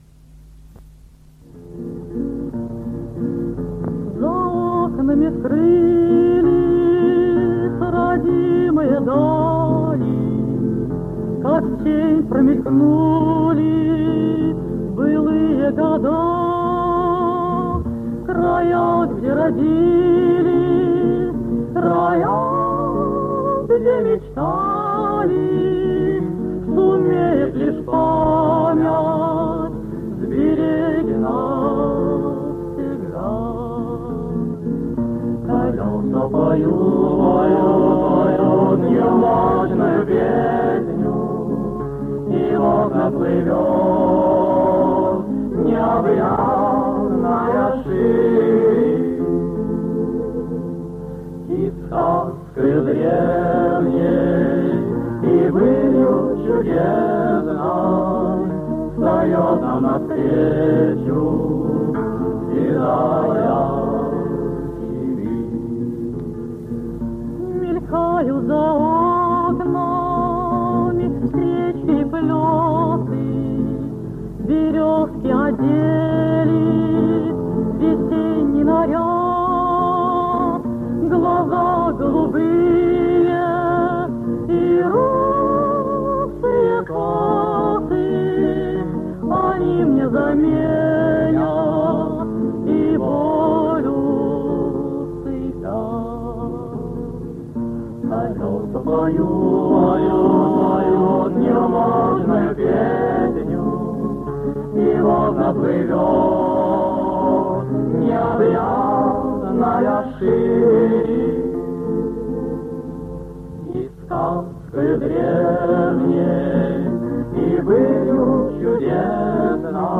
Аудиозаписи Третьего Московского конкурса студенческой песни
ДК МЭИ. 7 декабря 1961 года.
Ансамбль МГРИ под гитару